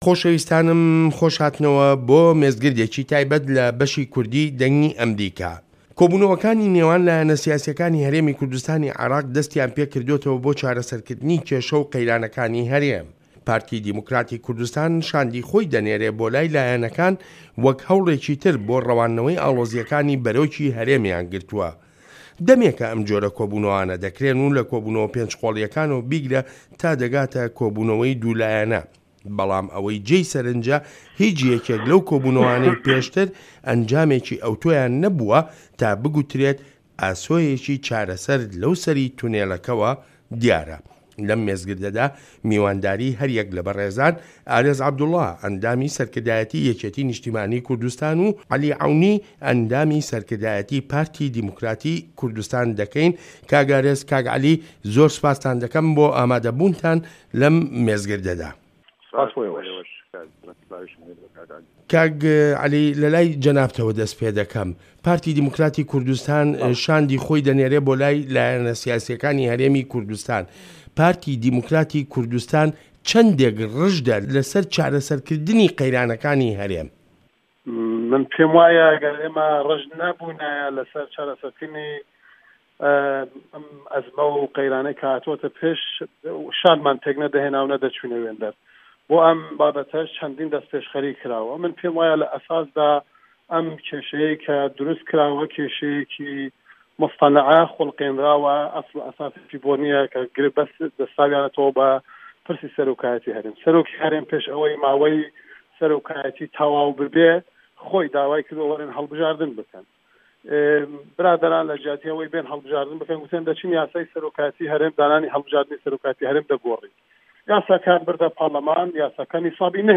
مێزگرد: لایەنەکانی هەرێمی کوردستان لە قەیران و ناکۆکییەوە بەرەو کوێ